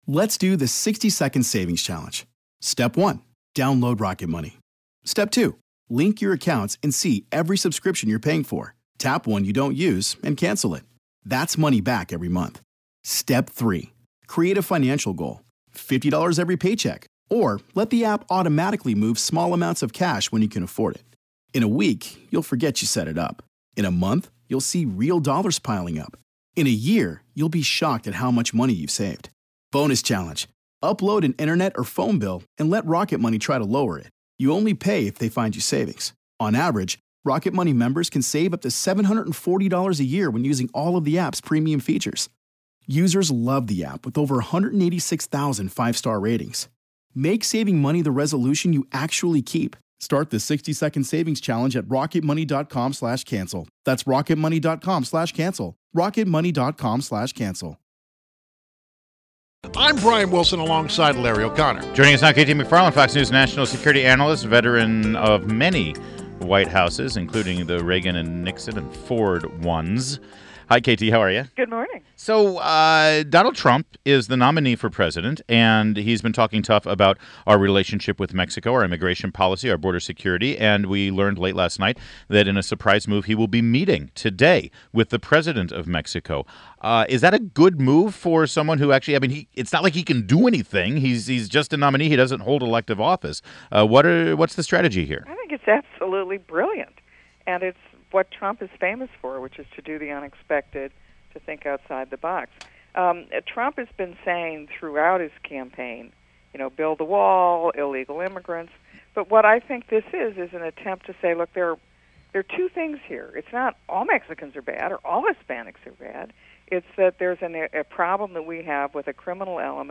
WMAL Interview - KT MCFARLAND - 08.31.16